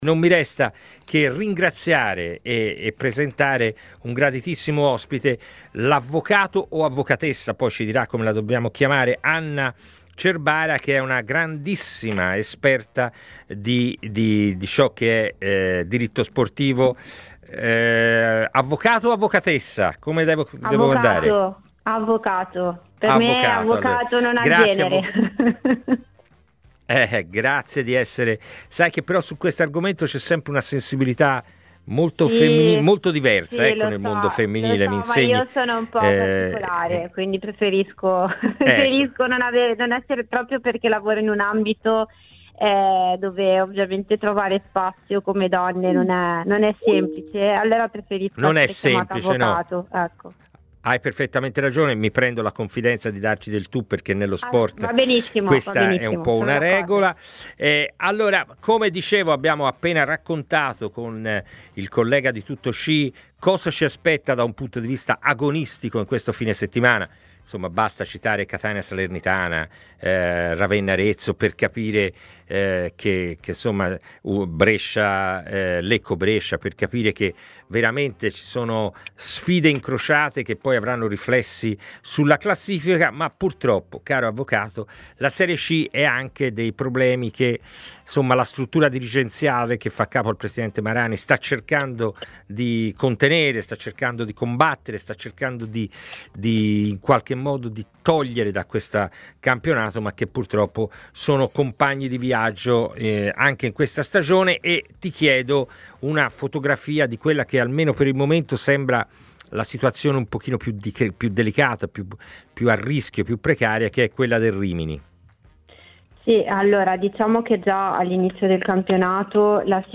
Entriamo nel tema principale: la Serie C vive anche quest’anno diverse criticità.